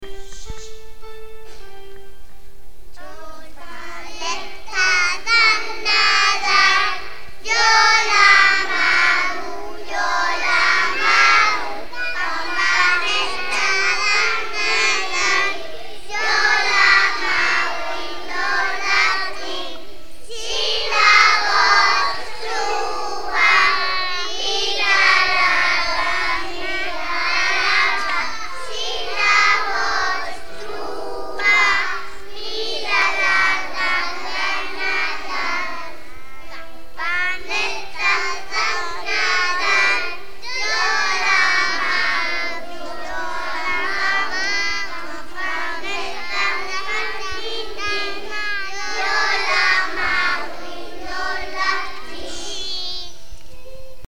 Avui a la tarda hem fet el concert de nadales .
Voleu escoltar la Nadala que hem cantat nosaltres??